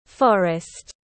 Forest /ˈfɒr.ɪst/